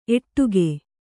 ♪ etṭuge